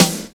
28 SNARE 2.wav